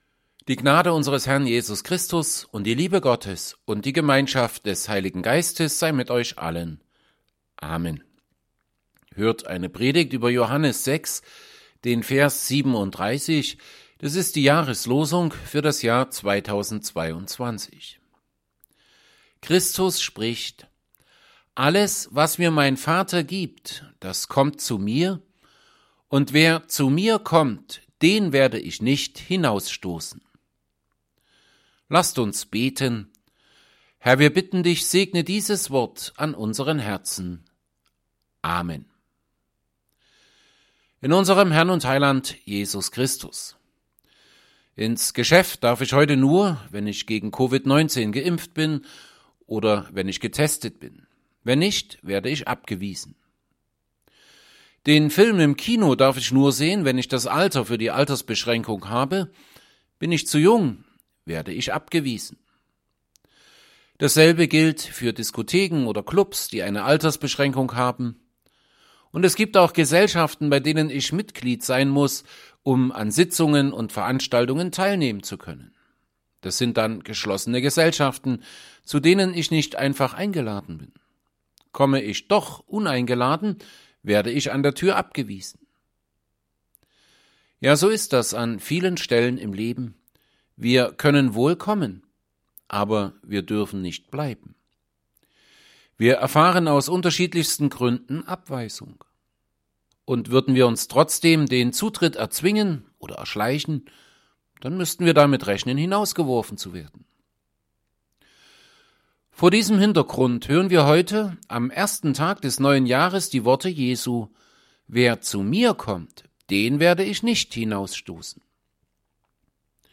Predigt_zu_Johannes_6_37.mp3